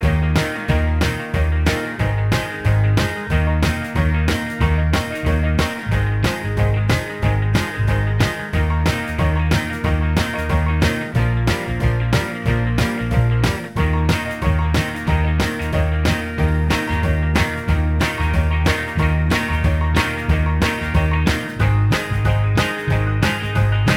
Minus Lead Rock 'n' Roll 2:26 Buy £1.50